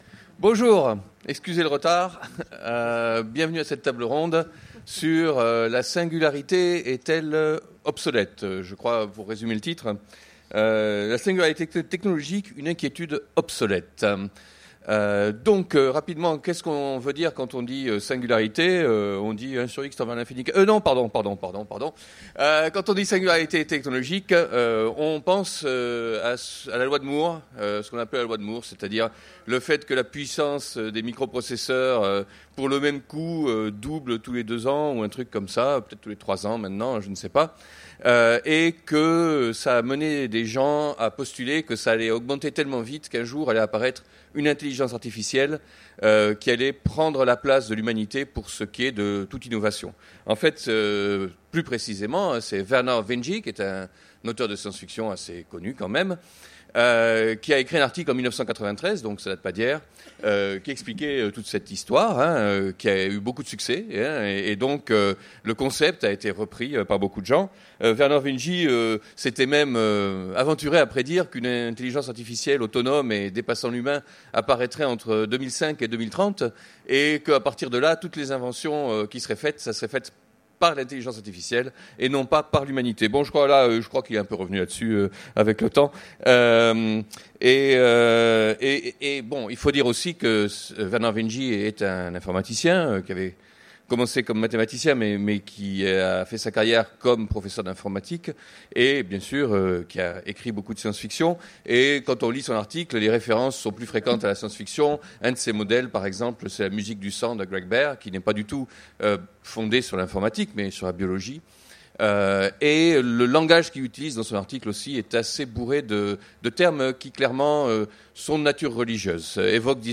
Utopiales 2015 : Conférence La Singularité technologique